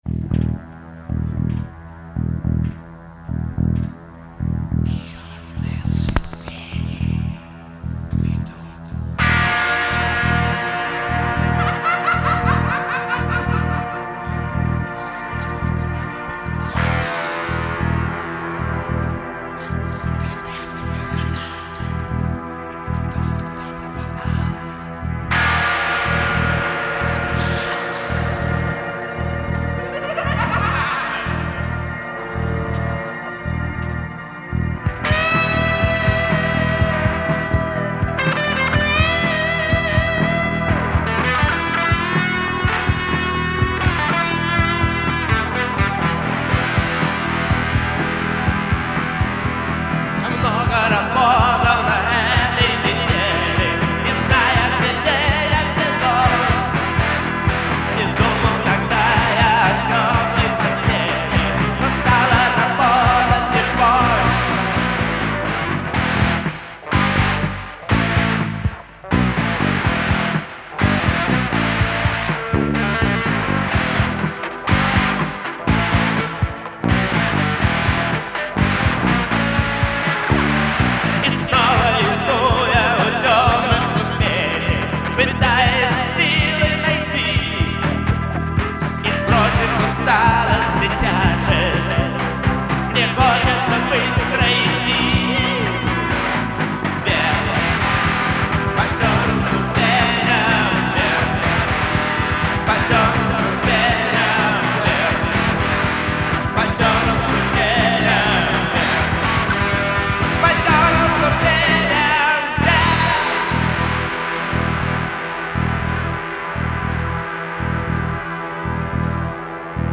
Сборник пробных записей, этюдов, репетиций
музыка, текст, вокал, гитары
барабаны, перкуссия, драм-машина
бэк-вокал, бас-гитара
клавиши (фортепиано, vermona, электроника)